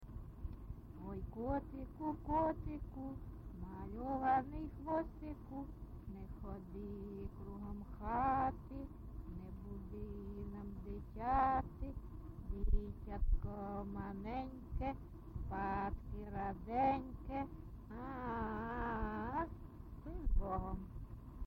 GenreLullaby
Recording locationMykhailivka, Shakhtarskyi (Horlivskyi) district, Donetsk obl., Ukraine, Sloboda Ukraine